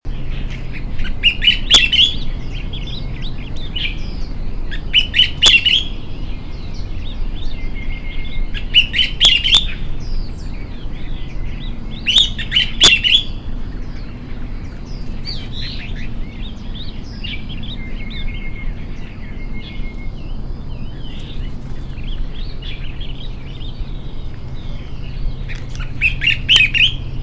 40-1白環鸚嘴鵯柴山2004aug11.mp3
物種名稱 白環鸚嘴鵯(台灣亞種) Spizixos semitorques cinereicapillus
錄音地點 高雄市 鼓山區 壽山
錄音環境 森林
行為描述 鳥叫 錄音器材 錄音: 廠牌 Denon Portable IC Recorder 型號 DN-F20R 收音: 廠牌 Sennheiser 型號 ME 67